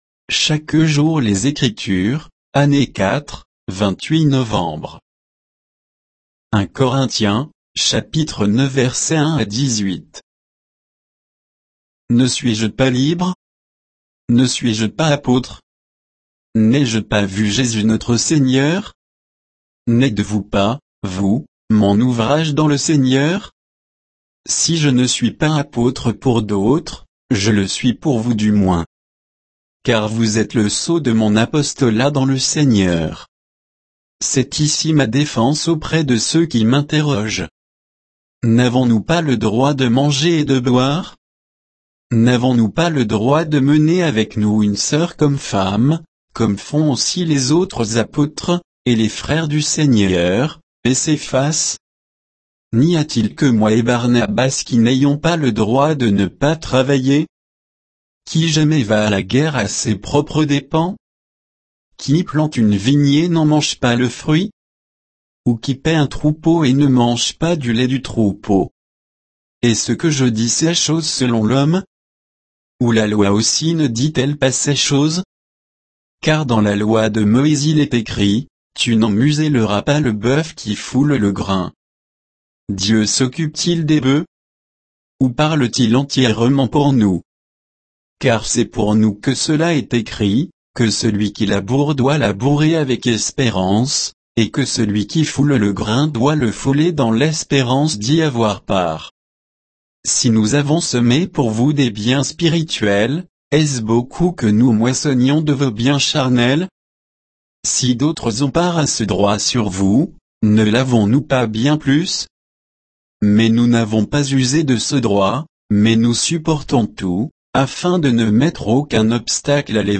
Méditation quoditienne de Chaque jour les Écritures sur 1 Corinthiens 9, 1 à 18